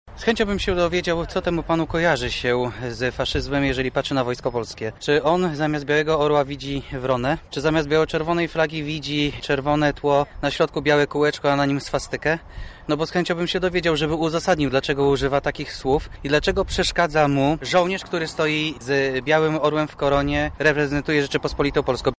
Zdarzenie komentuje przewodniczący Rady Miasta Lubartów Jacek Tomasiak